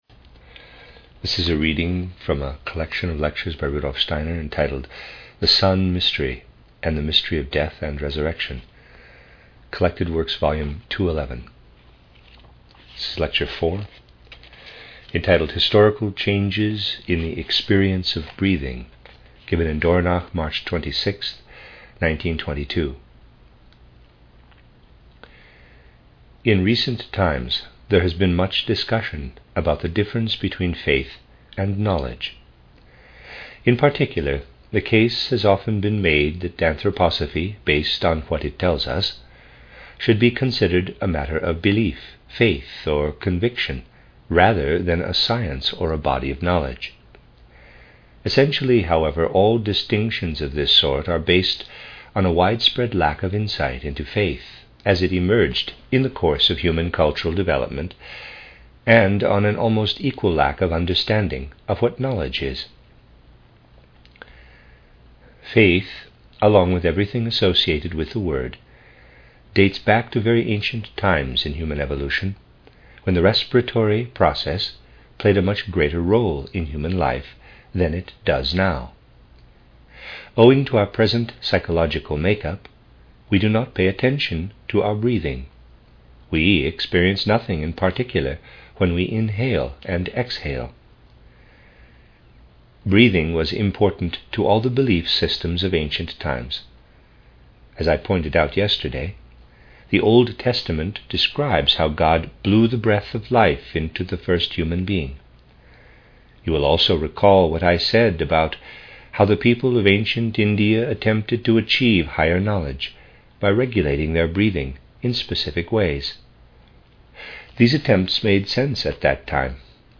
The Sun Mystery and the Mystery of Death and Resurrection (Exoteric and Esoteric Christianity), a collection of 12 lectures by Rudolf Steiner (CW 211) in the new collected works by Steinerbooks.